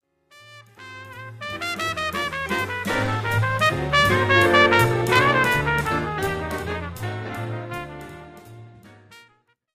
Music Transition; Jazz Ensemble Fade In And Out.